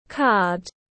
Thẻ bài tiếng anh gọi là card, phiên âm tiếng anh đọc là /kɑːd/
Card /kɑːd/